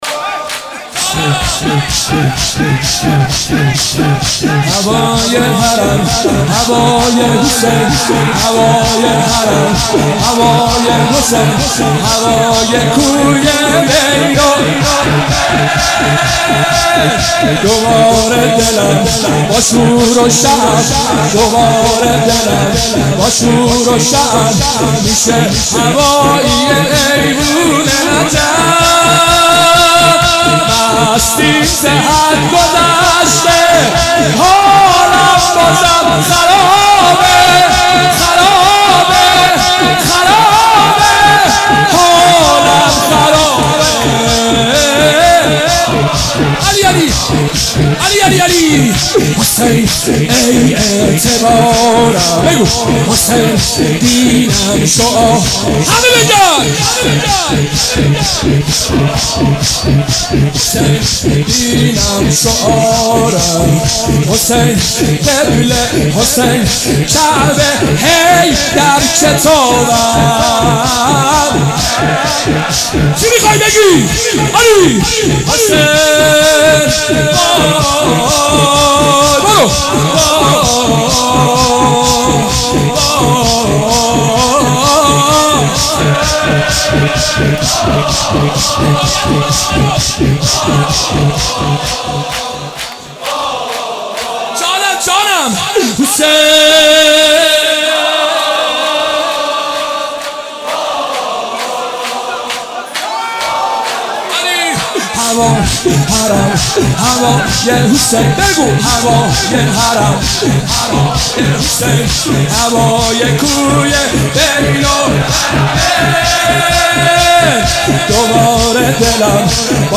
مدح